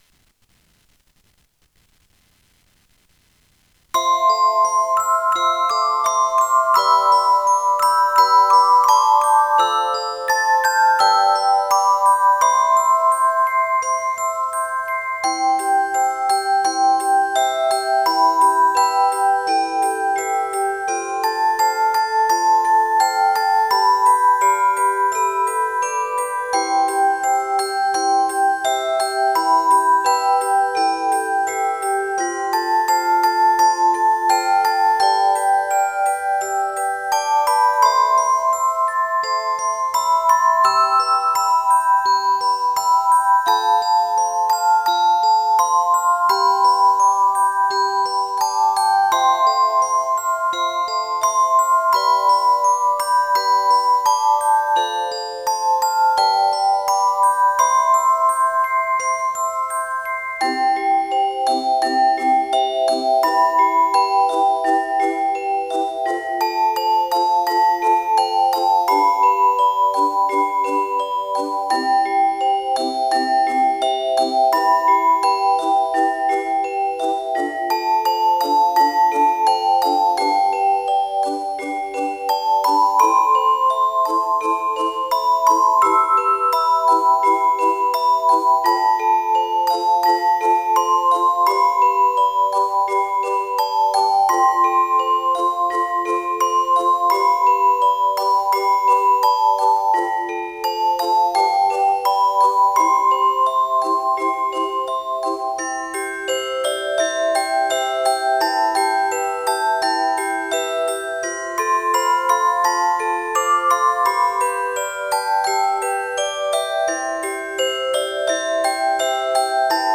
４．個人の作った曲（Desktop Music)　の紹介